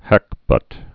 (hăkbŭt)